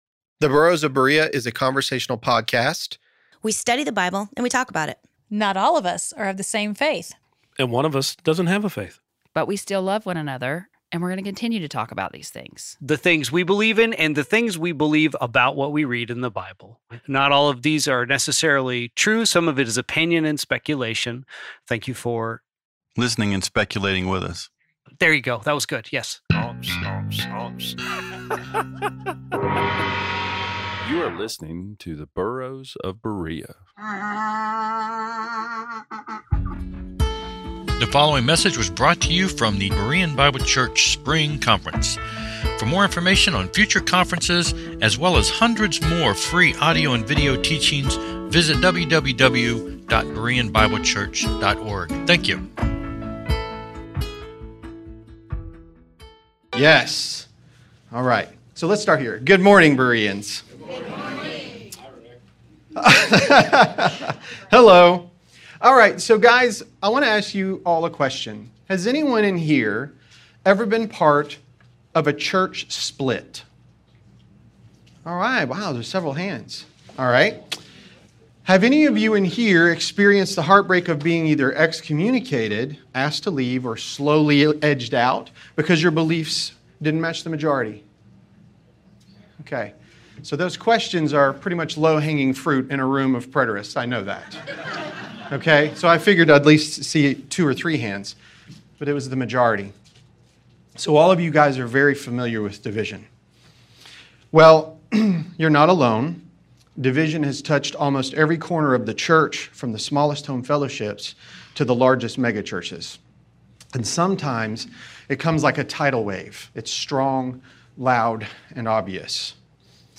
Berean Bible Conference